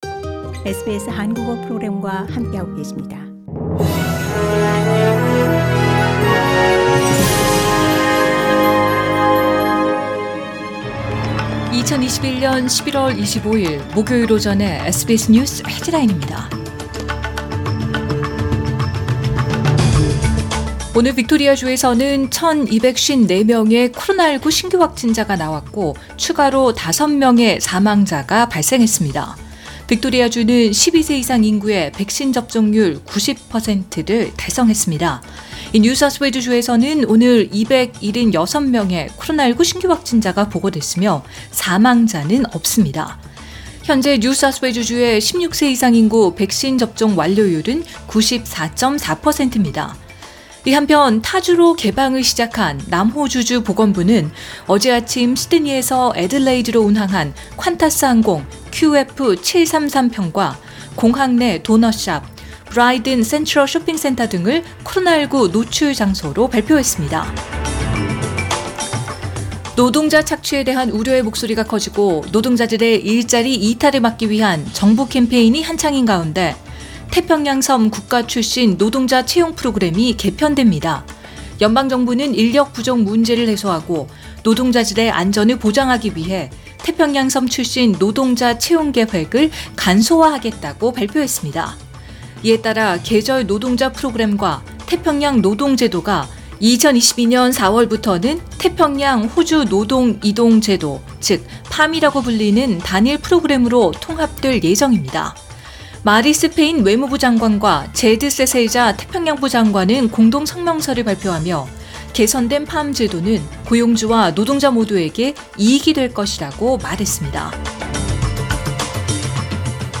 2021년 11월 25일 목요일 오전의 SBS 뉴스 헤드라인입니다.